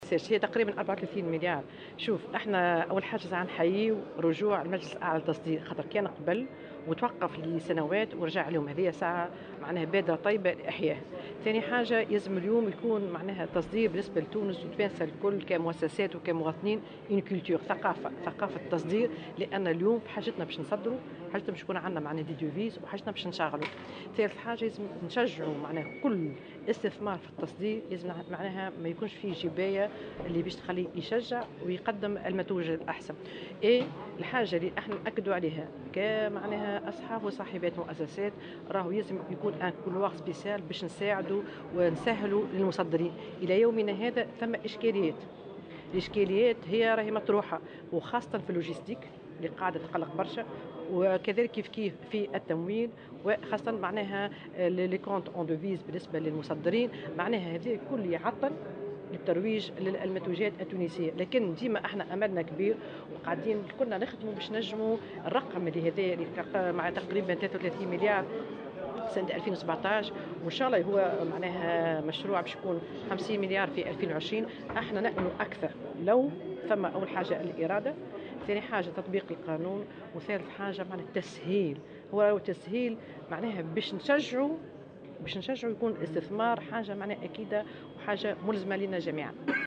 وأضافت بوشماوي على هامش انعقاد أول اجتماع للمجلس الأعلى للتصدير بمقر مركز النهوض بالصادرات أن هناك عديد الاشكاليات التي تعيق قطاع التصدير كمشاكل التمويل والجانب اللوجستي، مما يعطّل الترويج للمنتوجات التونسية.